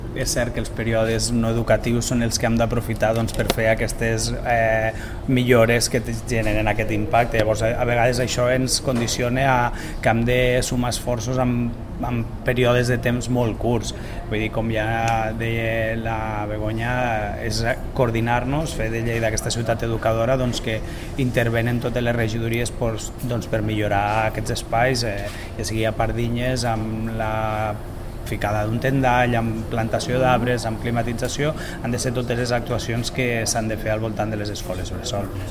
Tall de veu de l'alcaldessa accidental, Begoña Iglesias, sobre el sistema d'aerotèrmia instal·lat a l'EBM La Mitjana que permetrà disposar de refrigeració i ser més eficient a l'hivern.